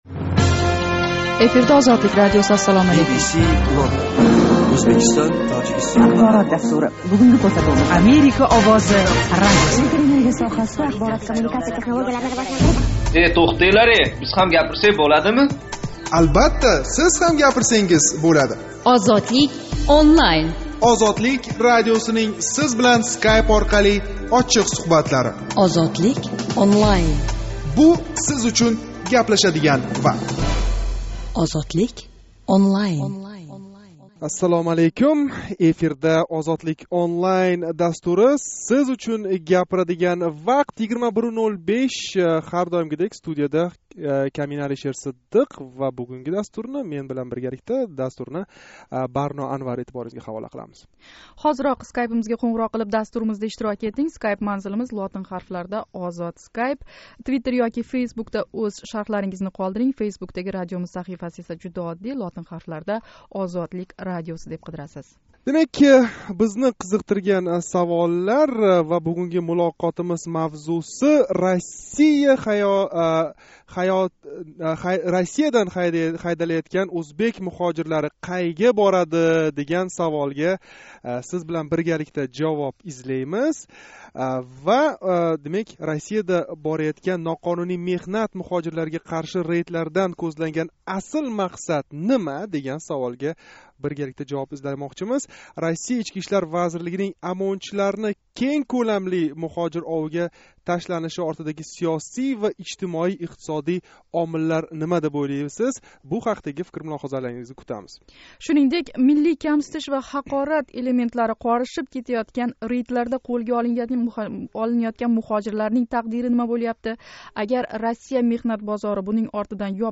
Ҳозирга қадар миллионлаб ўзбек оиласи учун “нажот қалъаси” бўлиб келган Россиядан муҳожирларнинг оммавий ҳайдалиши Ўзбекистондаги ижтимоий-иқтисодий вазиятга қандай таъсир кўрсатади? 5 август куни Тошкент вақти билан 21:05 да бошланган жонли суҳбатда шу ҳақда гаплашдик.